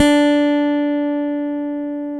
Index of /90_sSampleCDs/Roland LCDP02 Guitar and Bass/BS _Rock Bass/BS _Chapmn Stick